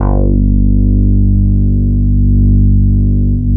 90 BASS   -L.wav